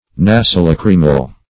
Search Result for " nasolachrymal" : The Collaborative International Dictionary of English v.0.48: Nasolachrymal \Na`so*lach"ry*mal\, a. [Naso- + lachrymal.]